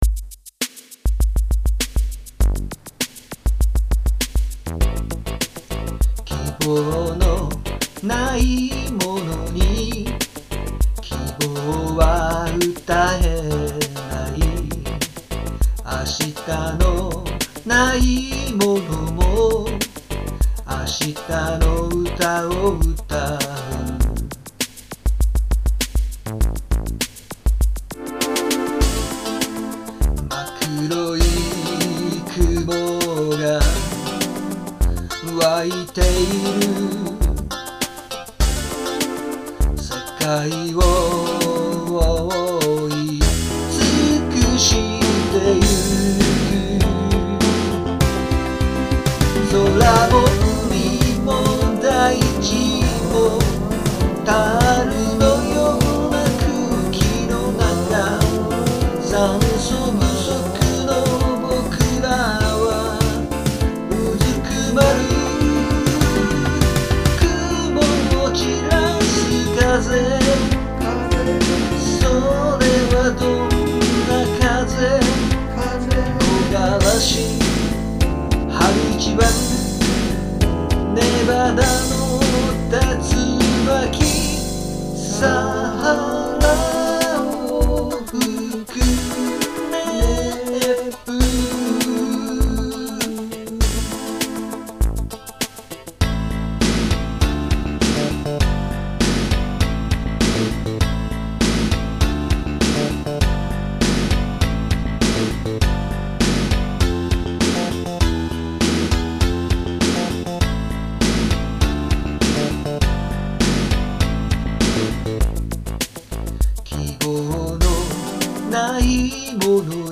久しぶりの楽曲作品です。
録音もかなり悪いのですが、特に詞がうまく書けませんでした。